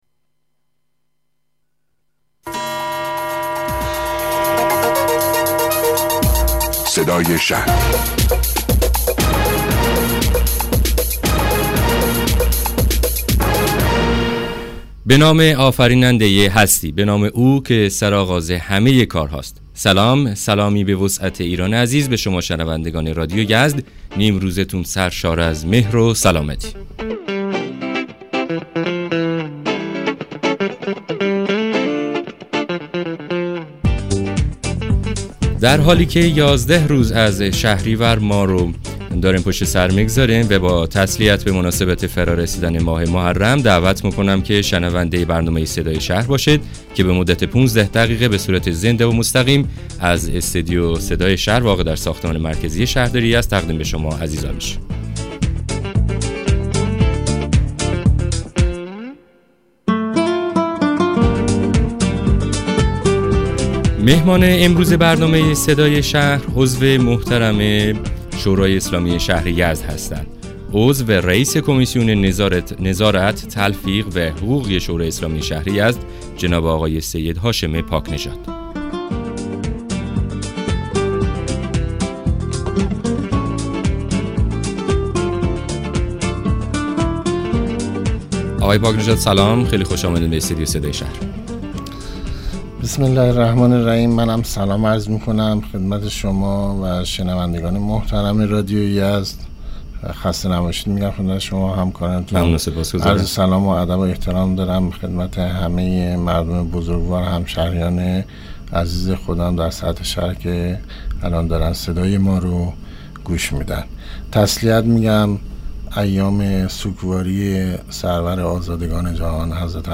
سید هاشم پاک‌نژاد؛ عضو شورای اسلامی شهر یزد و رئیس کمیسیون نظارت، تلفیق و حقوقی شورای اسلامی درباره قانون حفظ و گسترش فضای سبز در شهرها توضیحاتی ارائه می‌دهد.